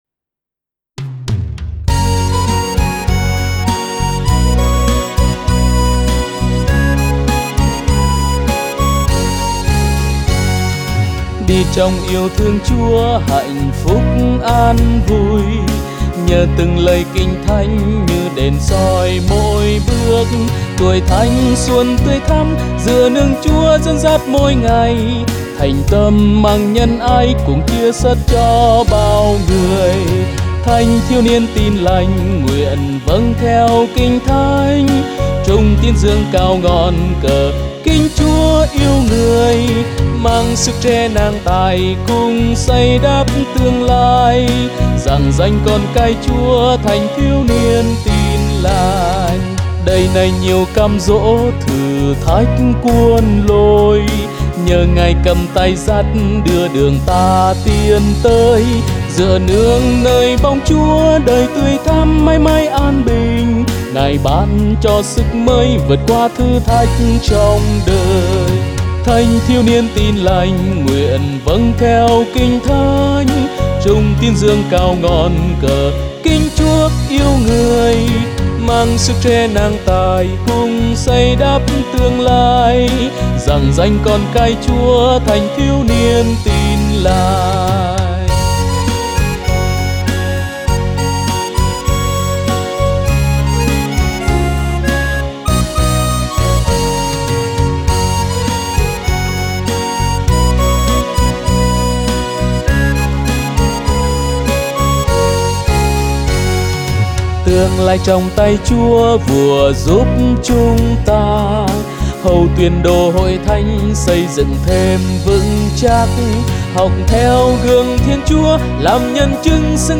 Audio Nhạc Nhạc Thánh Sáng Tác Mới Bài hát